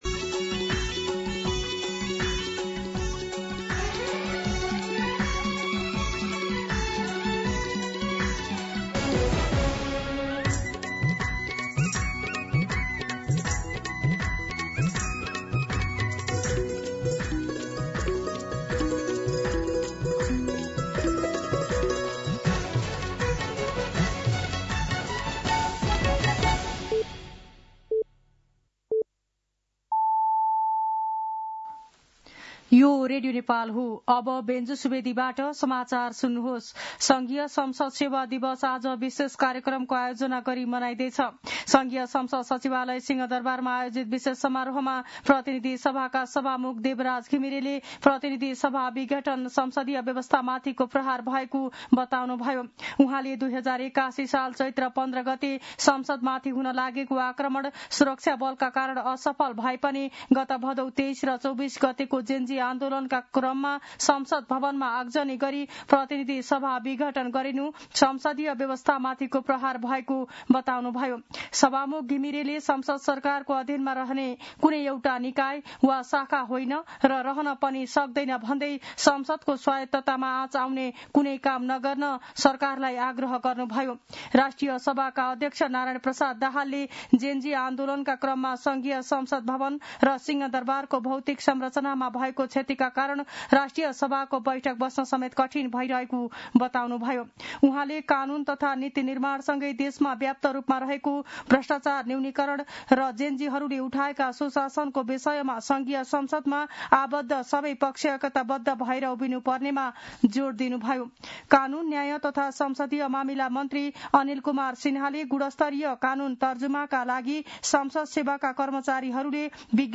दिउँसो ४ बजेको नेपाली समाचार : २८ मंसिर , २०८२